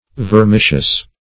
Search Result for " vermicious" : The Collaborative International Dictionary of English v.0.48: Vermicious \Ver*mi"cious\, a. [L. vermis a worm.] Of or pertaining to worms; wormy.